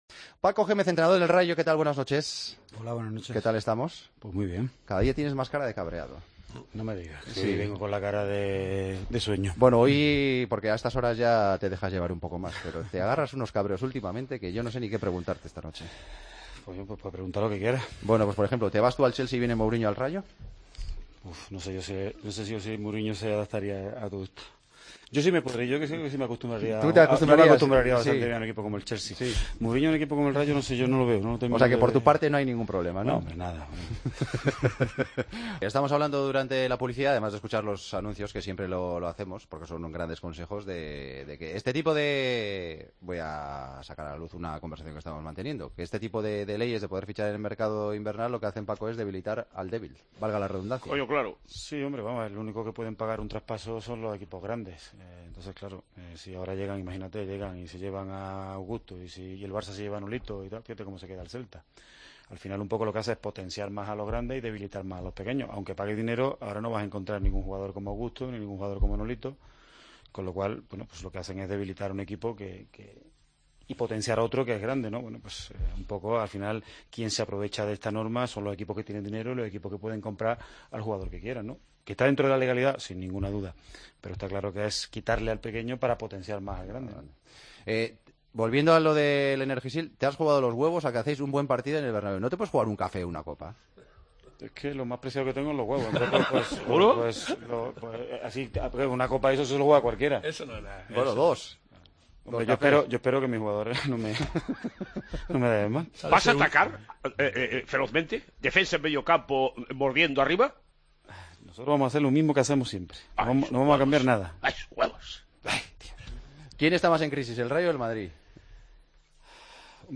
AUDIO: Recibimos la visita del entrenador del Rayo Vallecano, la semana en la que el equipo rayista juega en el Santiago Bernabéu: "No sé si...